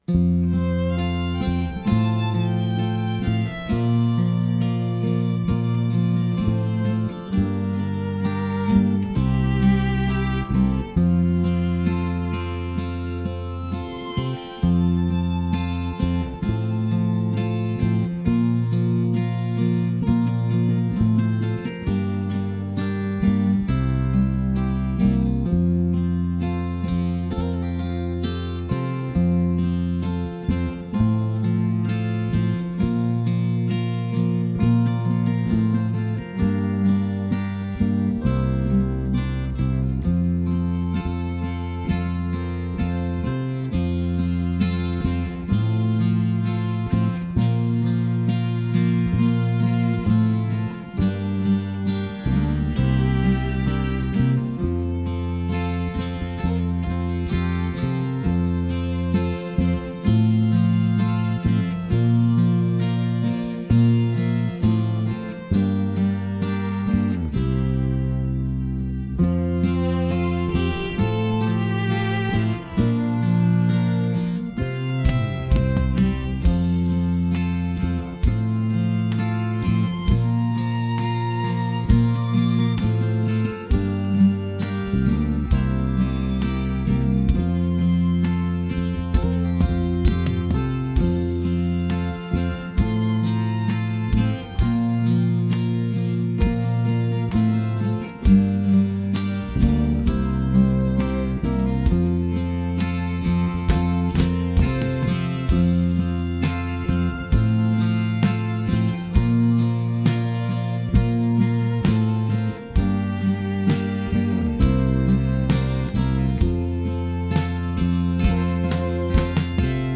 Key of F - Track Only - No Vocal